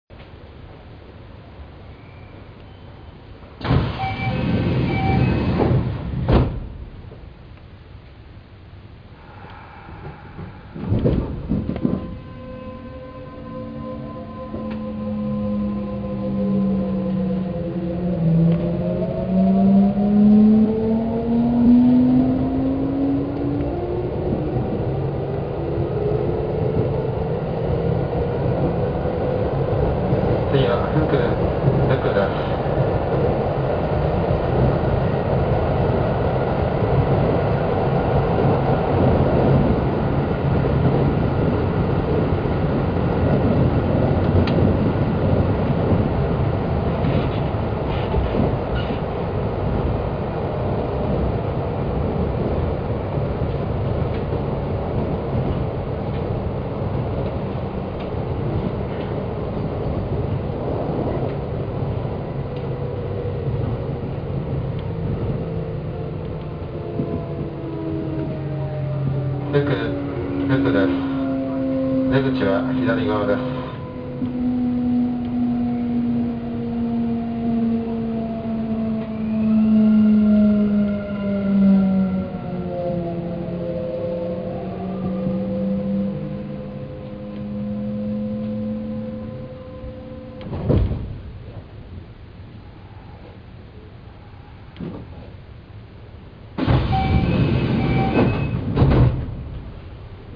・5800系走行音
【阪神なんば線】出来島〜福（1分40秒：785KB）
三菱GTOで、関東地方では都営5300形などで聞ける音の近縁となります。起動音は低音ですが、これは5300形の一部で嘗て聞くことが出来た音でもありました。